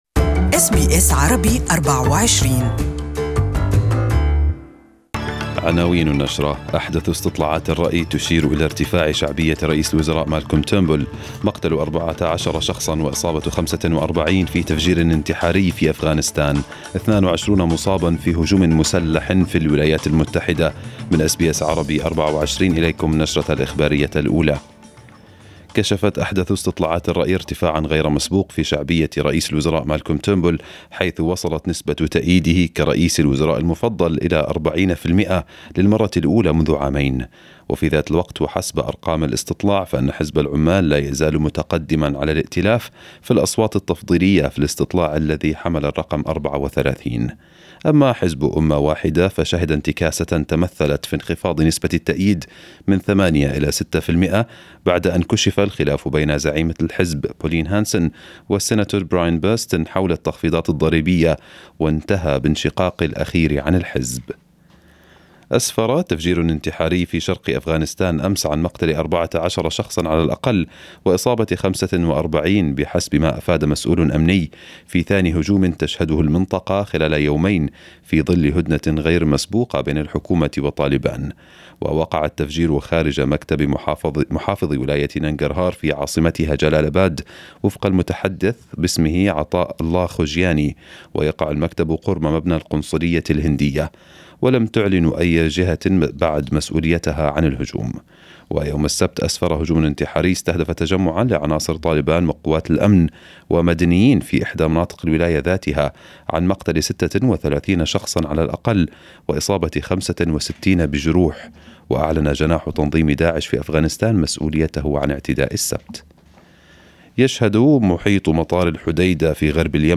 SBS Arabic 24 News Bulletin